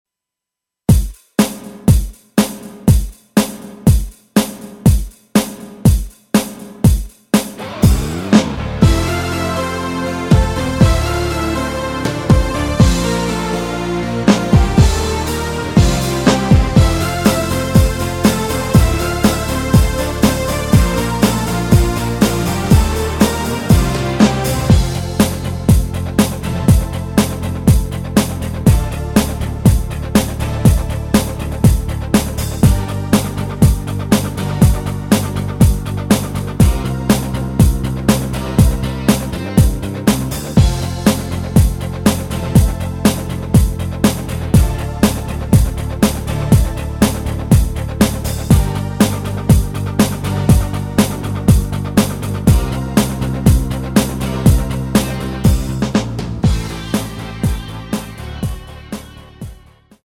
내린 MR
아래의 가사 까지 진행 되고 끝나도록 만들었습니다.
◈ 곡명 옆 (-1)은 반음 내림, (+1)은 반음 올림 입니다.
앞부분30초, 뒷부분30초씩 편집해서 올려 드리고 있습니다.
중간에 음이 끈어지고 다시 나오는 이유는